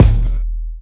brk_stcck_kick.wav